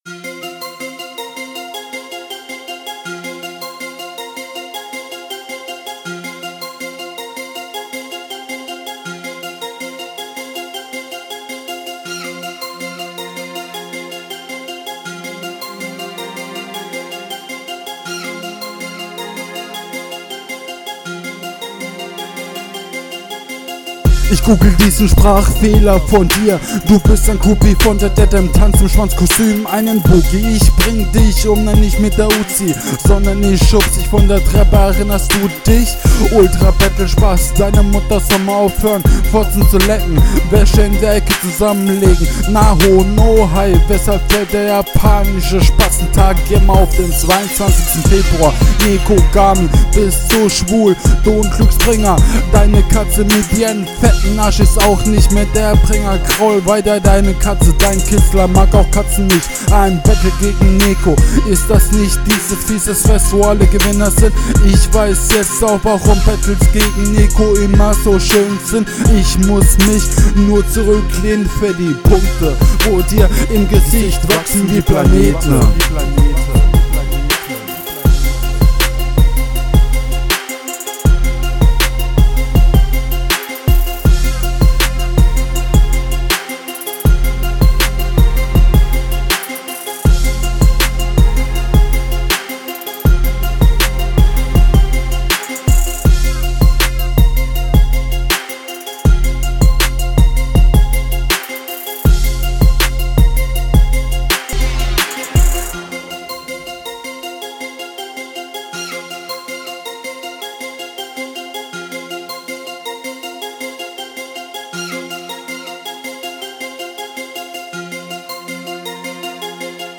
Cut doch den Beat am Ende was ist das denn.
Hier hast du deine Mische etwas verkackt, der Beat + Stimme kommt zu unsauber und …